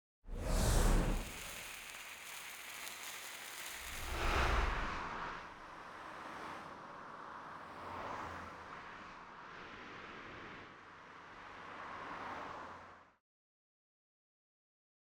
FORD_UNSUNG_Finale_v03_ST_SFX.wav